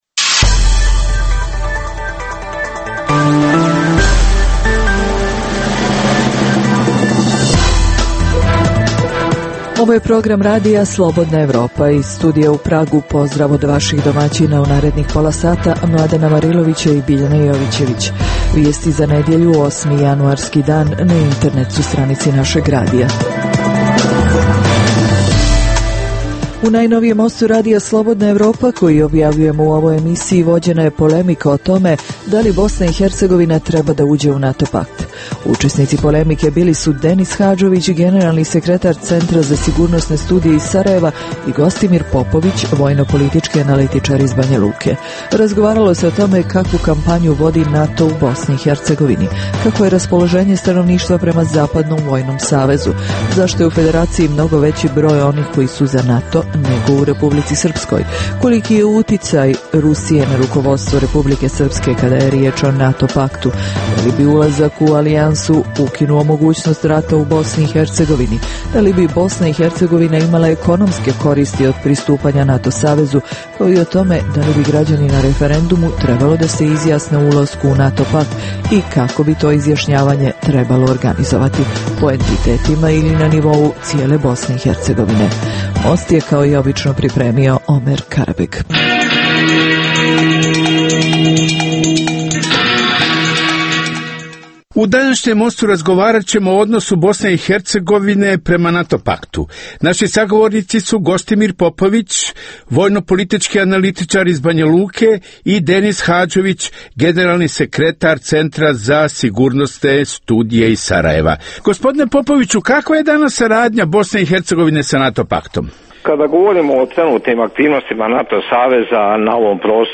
U Mostu RSE vođena je polemika o tome da li BiH teba da uđe u NATO.